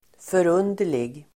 Ladda ner uttalet
Uttal: [för'un:der_lig]